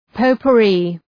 Προφορά
{,pəʋpʋ’ri:}